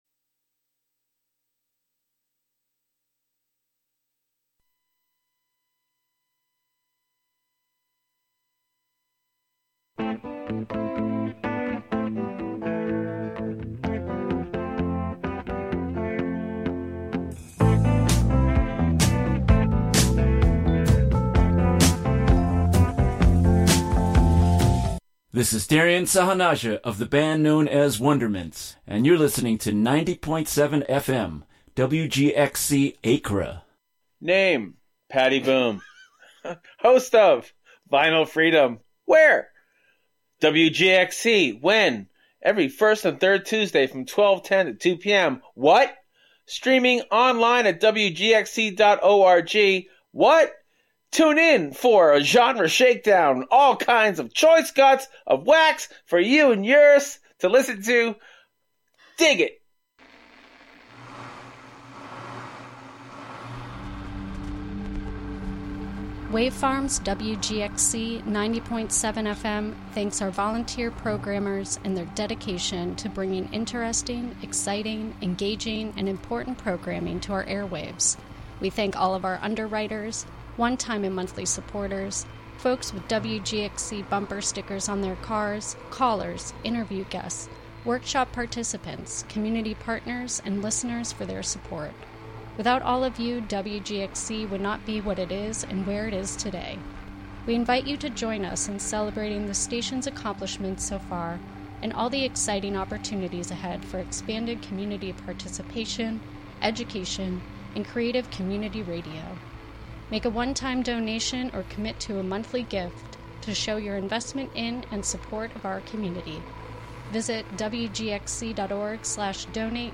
broadcast live from WGXC's Catskill studio.